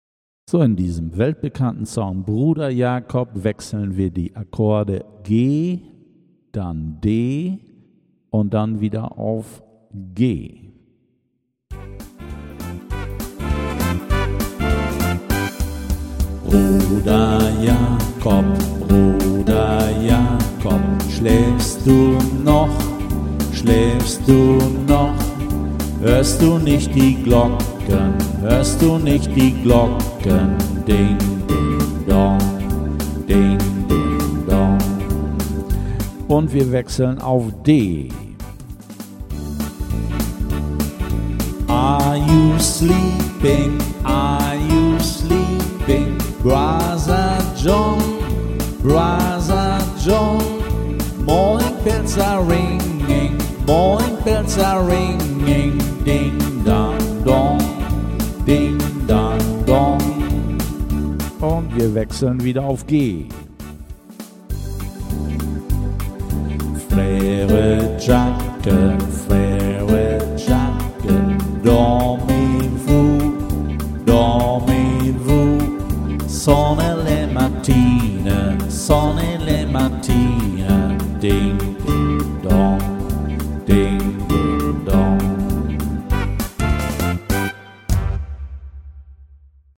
I play this song on my Tyros 4 Yamaha Keyboard.